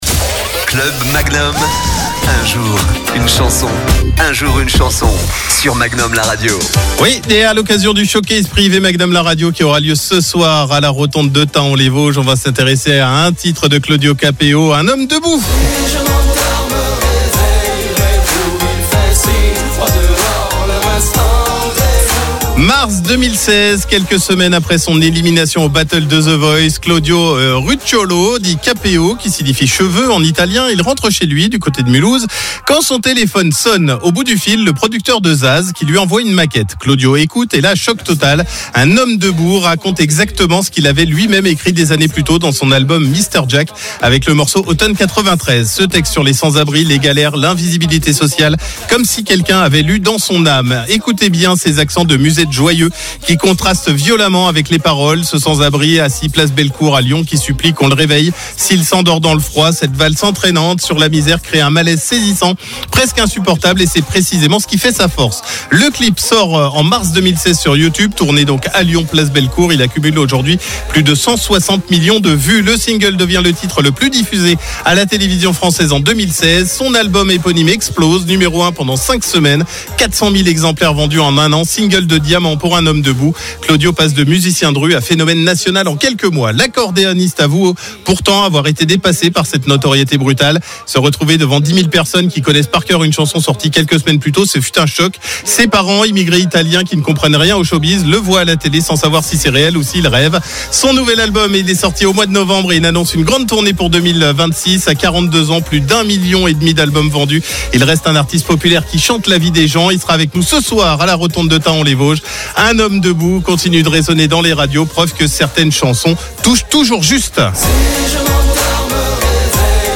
Ce texte sur les sans-abris, les galères, l'invisibilité sociale, comme si quelqu'un avait lu dans son âme. Écoutez bien ces accents de musette joyeux qui contrastent violemment avec les paroles : ce sans-abri assis Place Bellecour qui supplie qu'on le réveille s'il s'endort dans le froid. Cette valse entraînante sur la misère crée un malaise saisissant, presque insupportable.